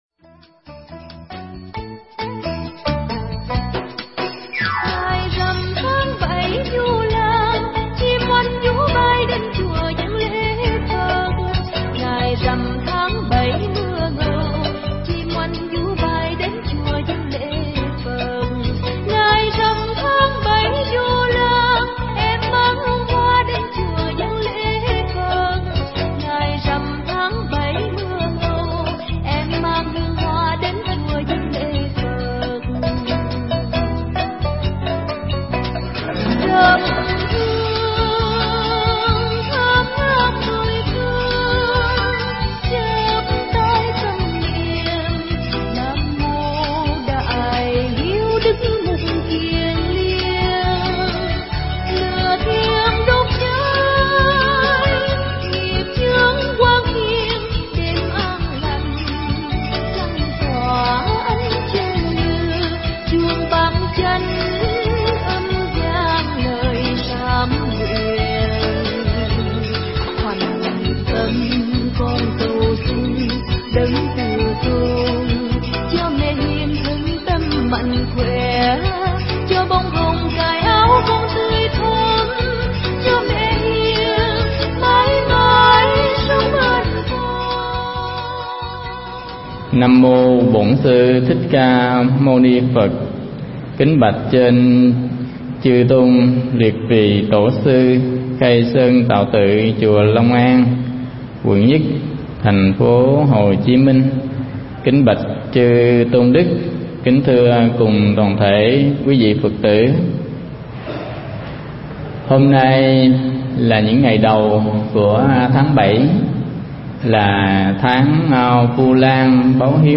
Pháp âm Biết Ơn Đáp Đền
Mp3 Thuyết Giảng Biết Ơn Đáp Đền
thuyết giảng tại Chùa Long An